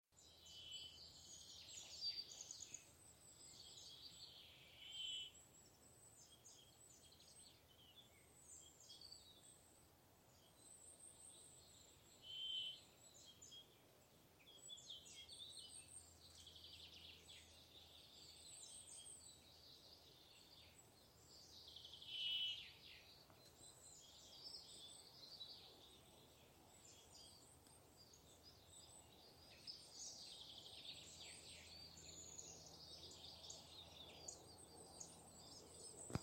Brambling, Fringilla montifringilla
NotesDziesma.